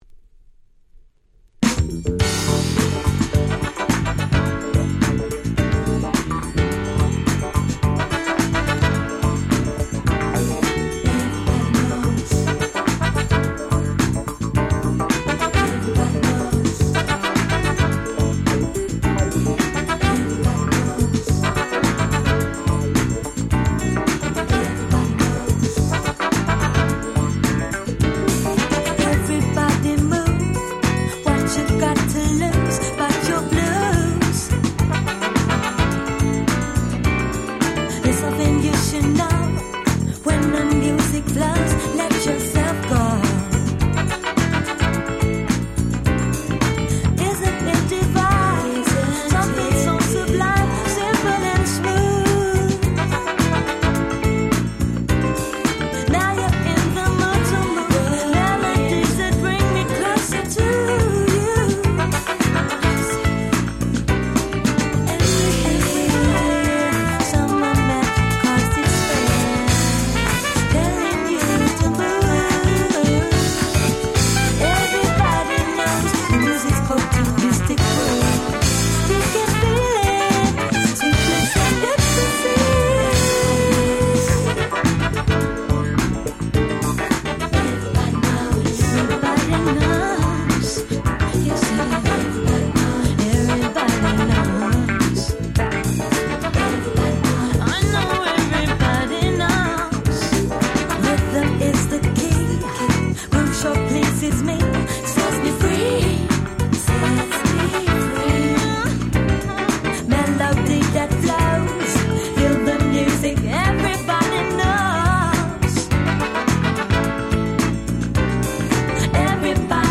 伸びやかな女性Vocalも相まって最強の歌物に仕上がっております！！